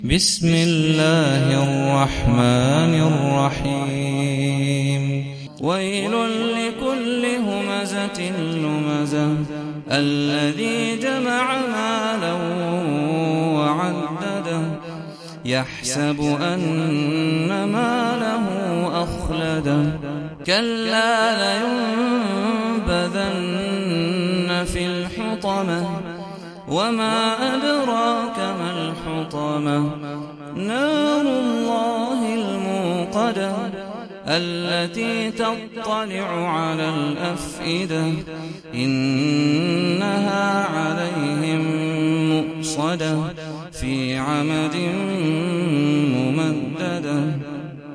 سورة الهمزة - صلاة التراويح 1446 هـ (برواية حفص عن عاصم
جودة عالية